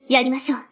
Enjoy some Weeb Anime Voices.